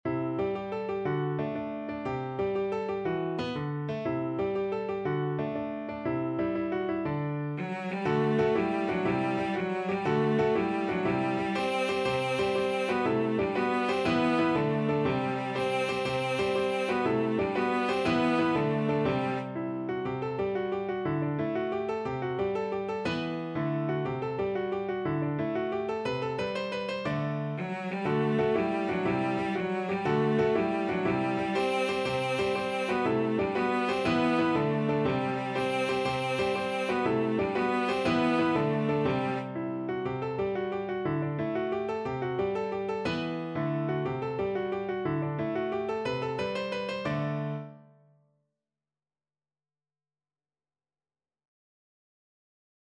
Cello
G major (Sounding Pitch) (View more G major Music for Cello )
With energy .=c.120
6/8 (View more 6/8 Music)
Classical (View more Classical Cello Music)